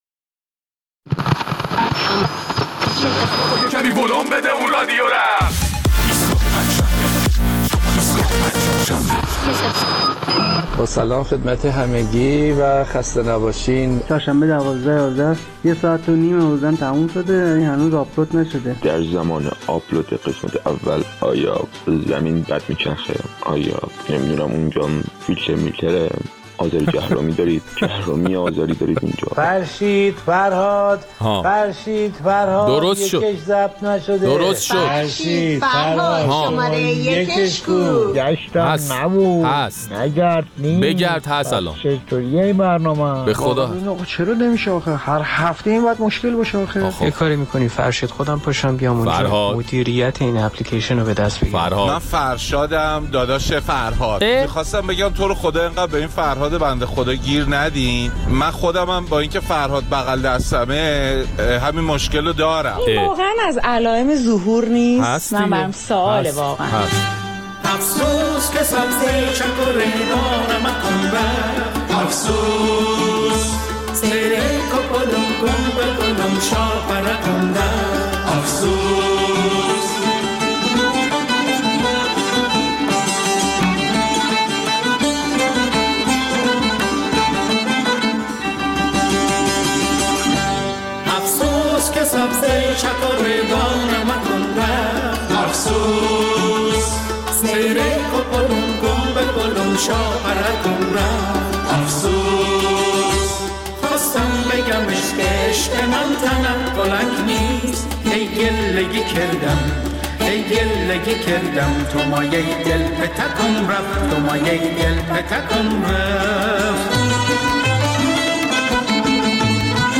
در این برنامه ادامه نظرات شنوندگان ایستگاه فردا را در مورد وضعیت اقتدار امنیتی نظام پس از ترور محسن فخری‌زاده می‌شنویم.